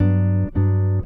gtr_85.wav